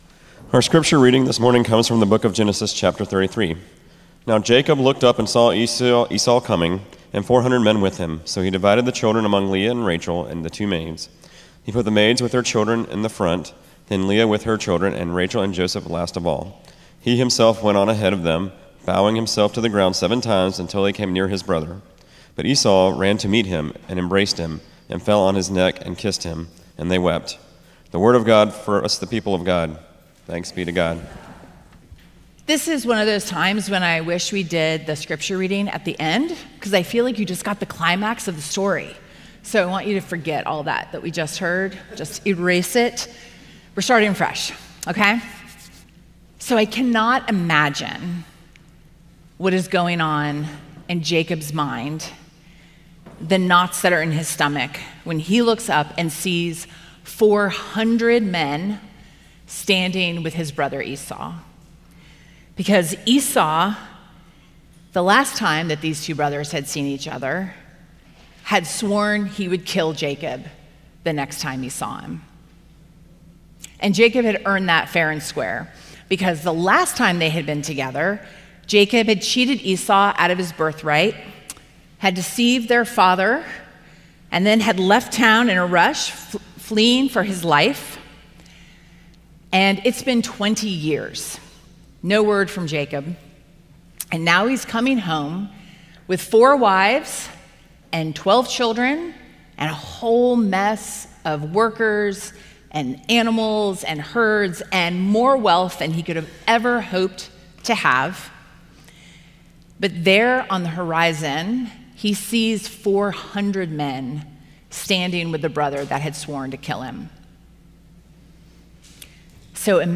“humbled” Sermon Series, Week 3